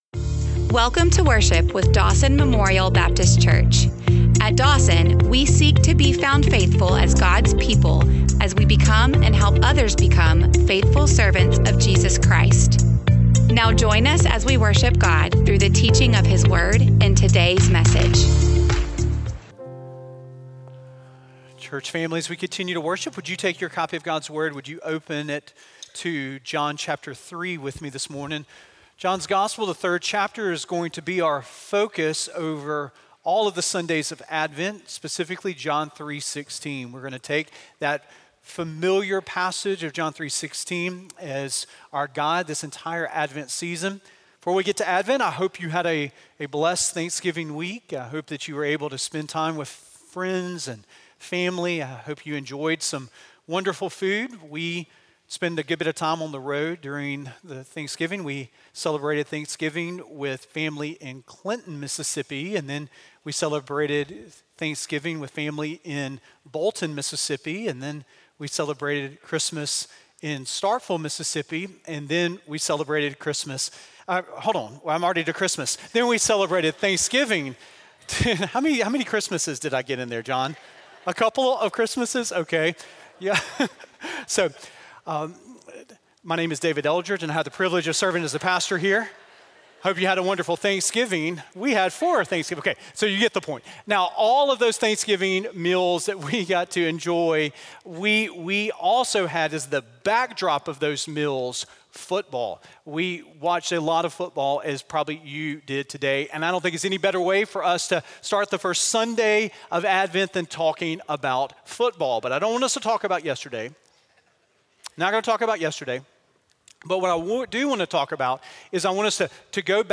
Teaching sermons presented during Sunday morning worship experiences with the Dawson Family of Faith, Birmingham, Alabama.